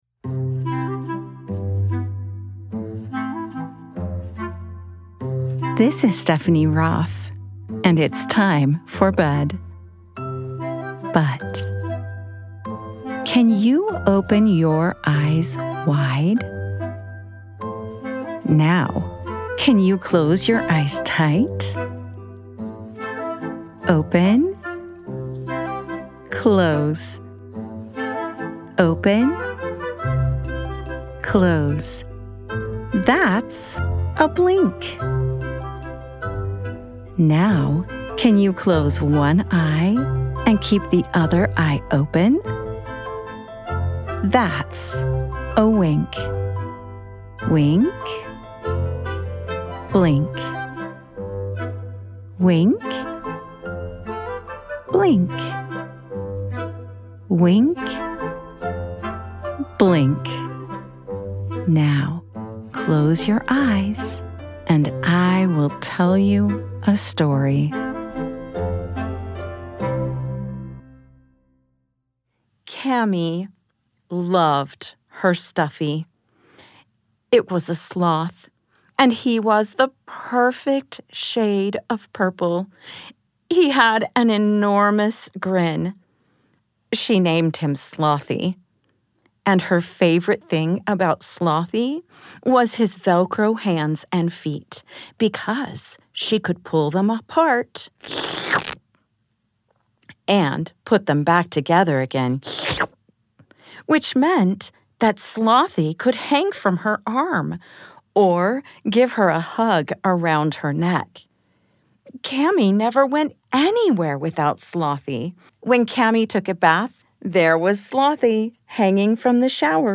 Where's My Stuffy?!: A Mindful Bedtime Story for Kids
@ wink-bedtime-stories Wink is a production of BYUradio and is always ad and interruption free.